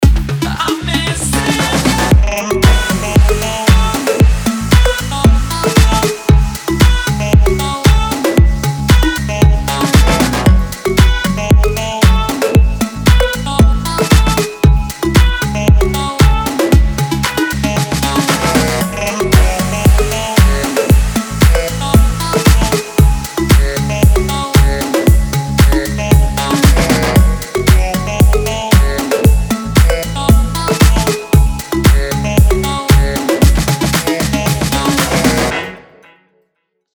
громкие
deep house
EDM
басы
house